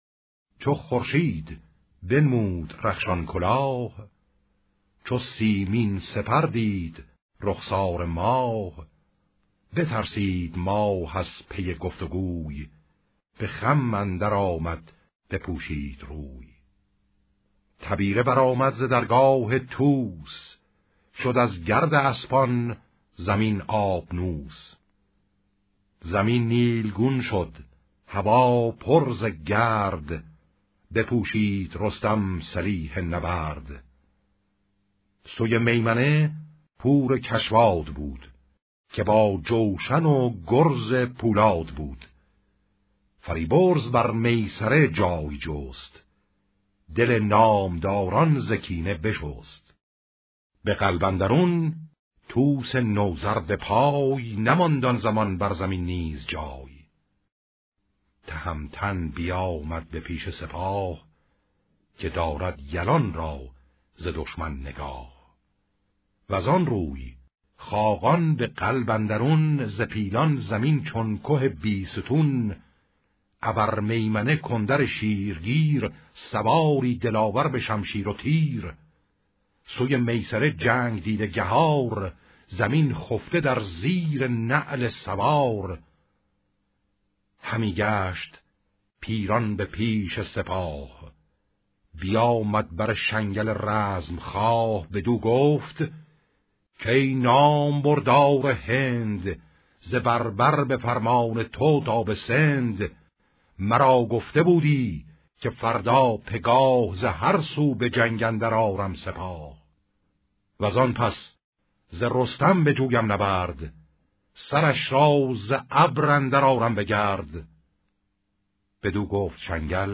شاهنامه خوانی